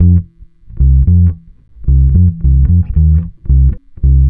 Track 15 - Bass 06.wav